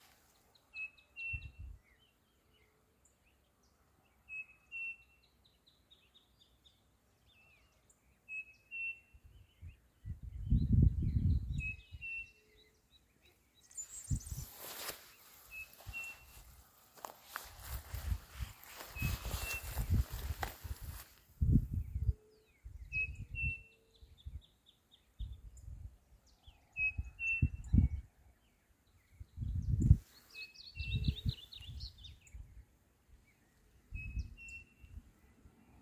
Striped Cuckoo (Tapera naevia)
Location or protected area: Lamadrid
Condition: Wild
Certainty: Recorded vocal